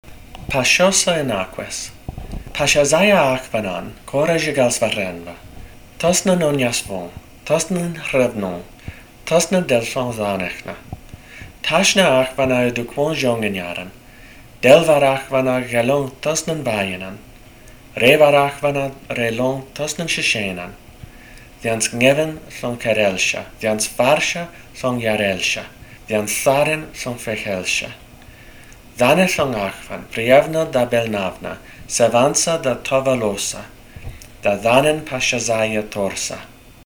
Páshósá-Ïnáqes - A native religious praise poem